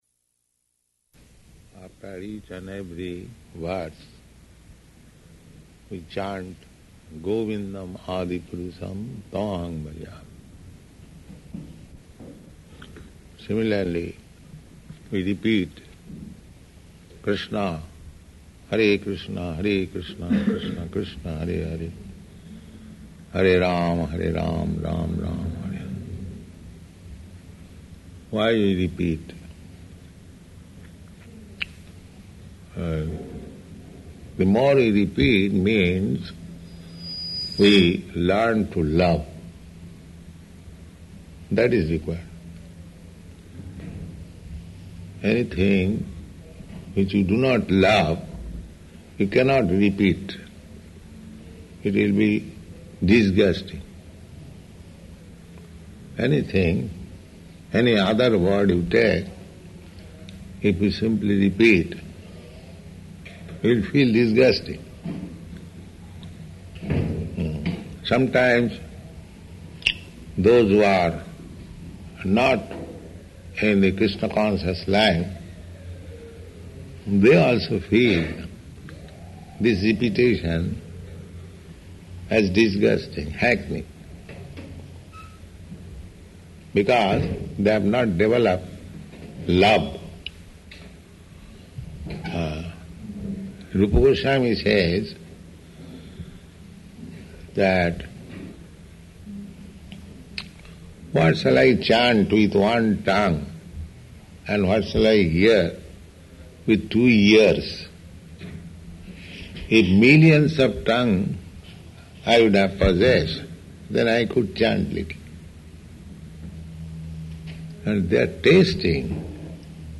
Lecture [partially recorded]
Location: Los Angeles